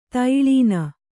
♪ taiḷīna